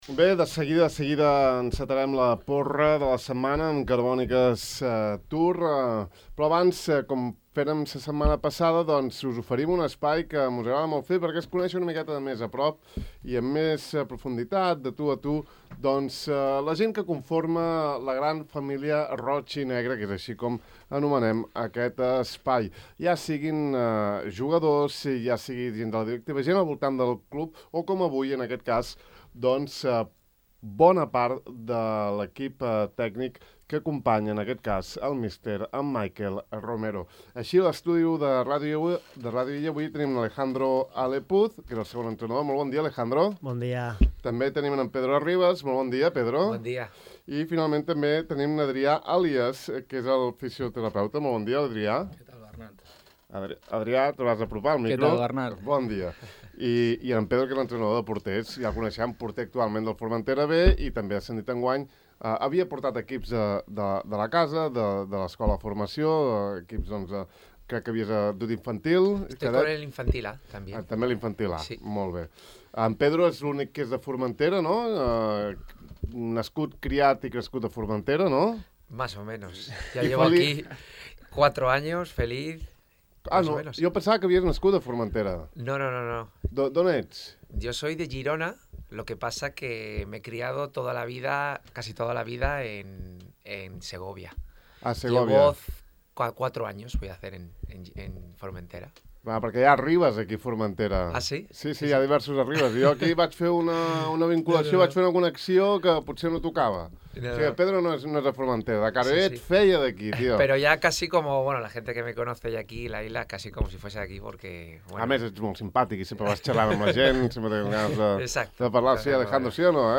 La setmana passada vam encetar el nostre nou espai ‘La gran família roig-i-negre’ inclòs en el De far a far. A través d’entrevistes disteses coneixerem alguns dels protagonistes de la SD Formentera, des dels jugadors del primer equip, equip tècnic, entrenadors de l’escola de futbol i altres integrants del club.